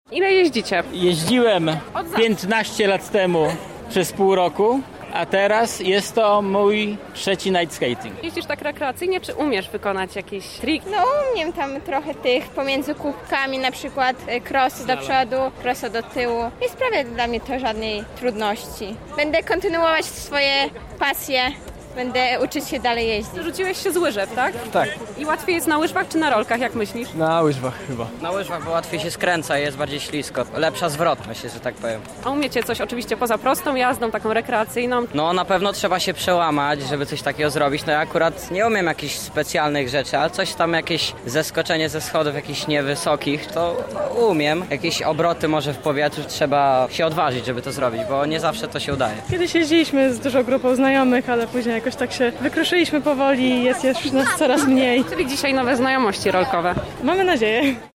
Nasza reporterka zapytała rolkarzy o ich doświadczenie.